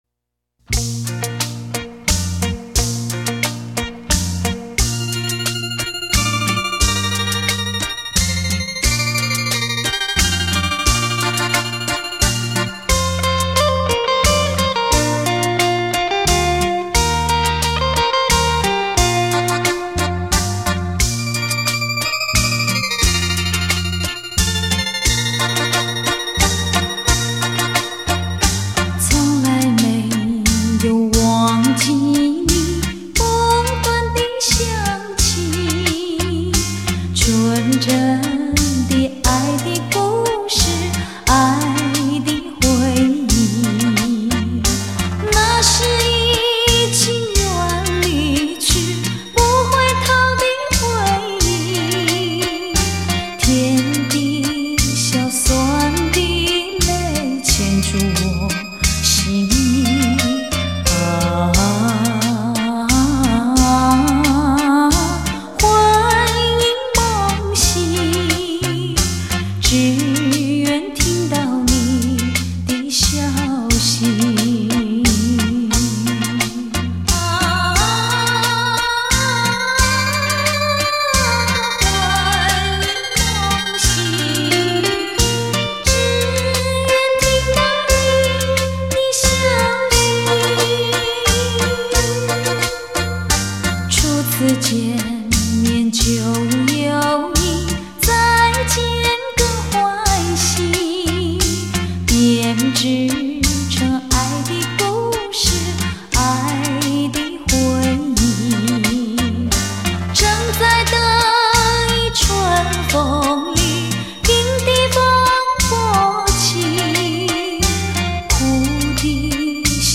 当一首首熟悉而动听的老歌再次响起，